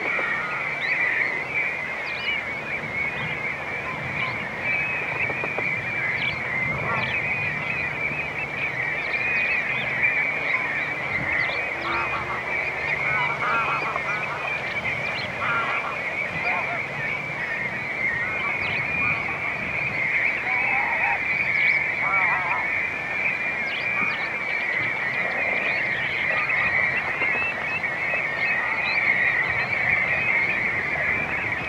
goudplevier
🔭 Wetenschappelijk: Pluvialis apricaria
♪ contactroep
goudplevier_roep.mp3